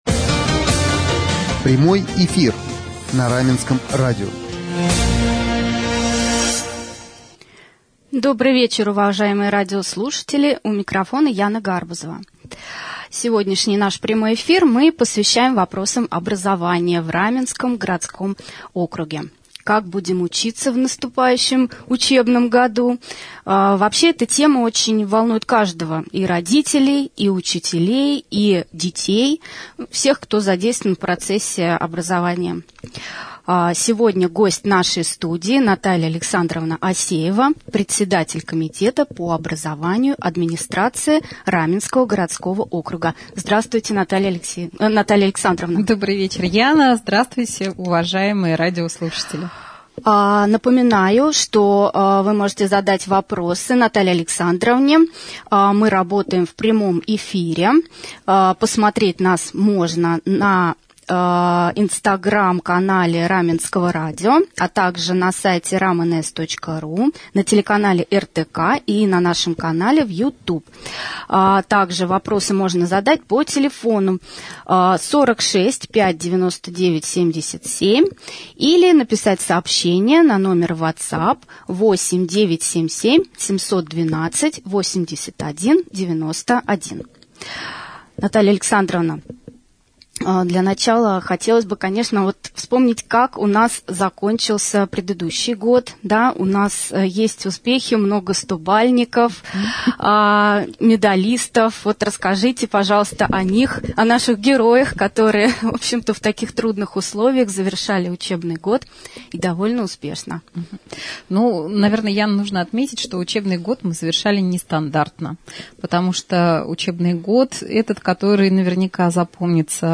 Наталья Александровна Асеева, председатель Комитета по образованию, стала гостем вечернего эфира на Раменском радио.